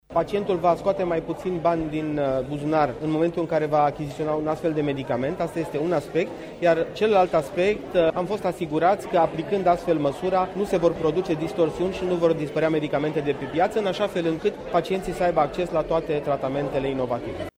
Ministrul Sănătății, Florian Bodog spune ce înseamnă acest ordin pentru pacienți: